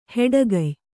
♪ heḍagay